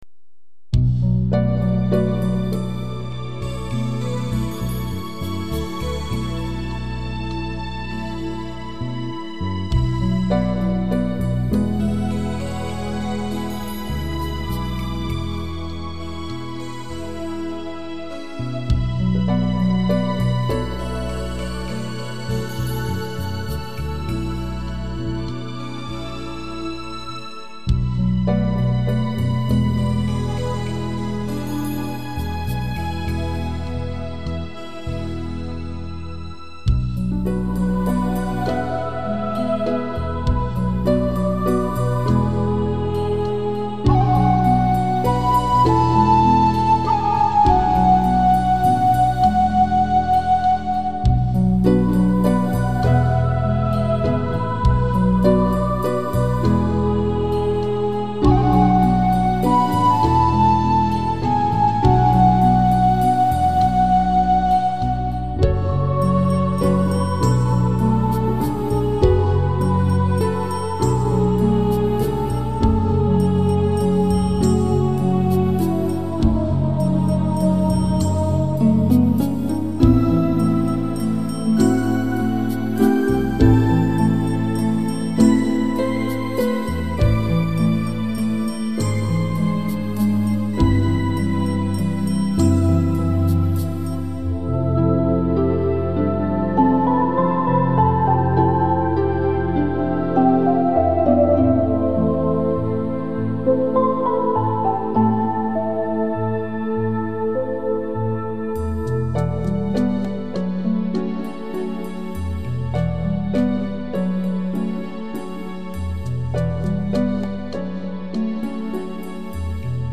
Music for relaxation and reflection